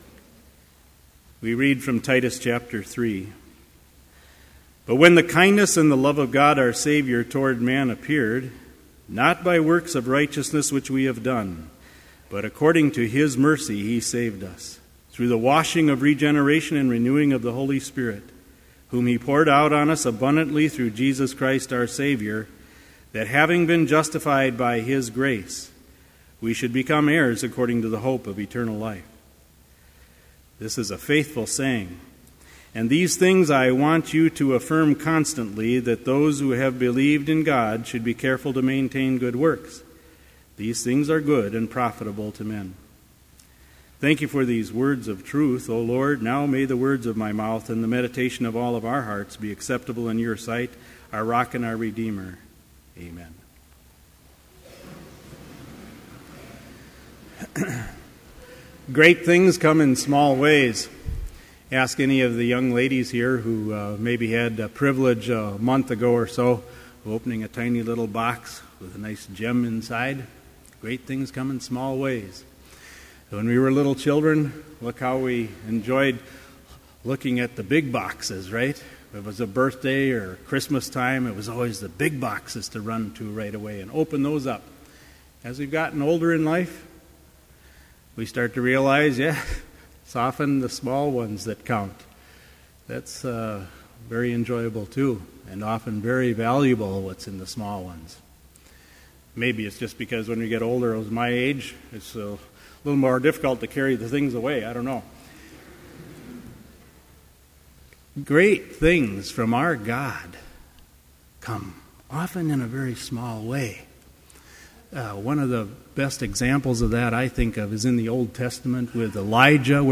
Complete service audio for Chapel - January 31, 2013